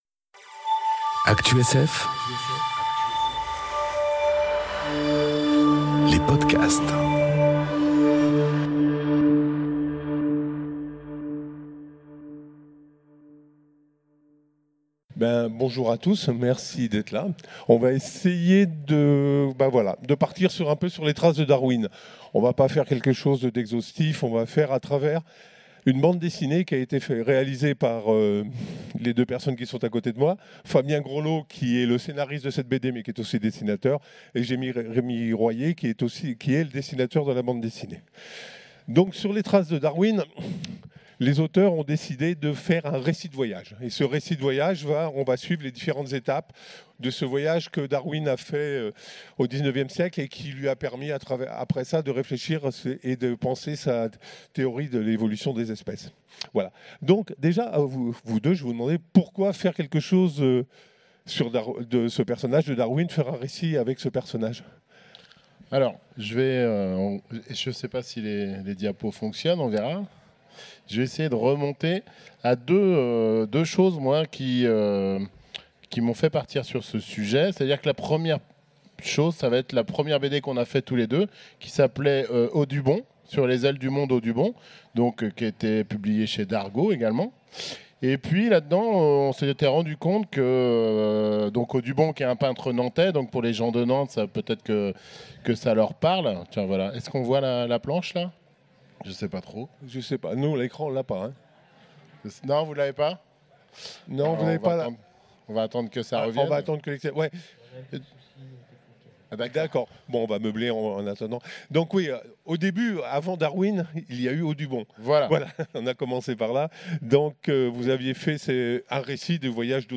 Conférence Sur les traces de Darwin enregistrée aux Utopiales 2018